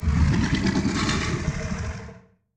Sfx_creature_bruteshark_idle_03.ogg